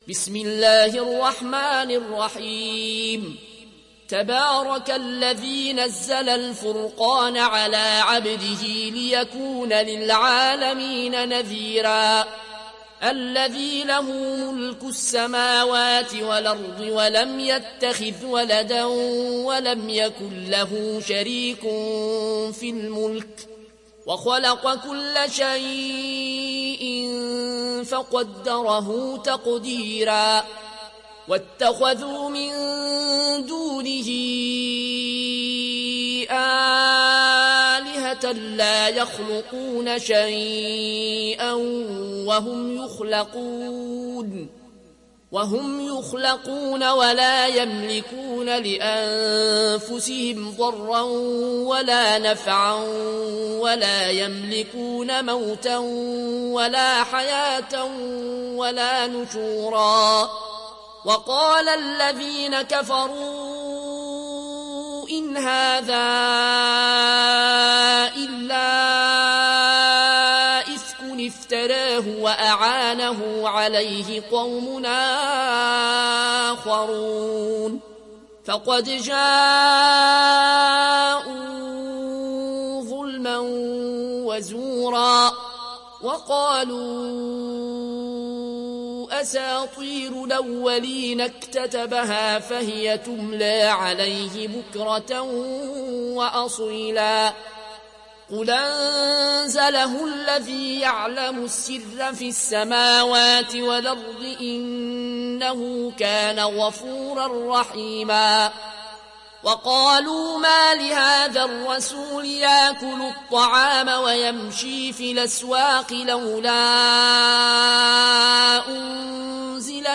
روایت ورش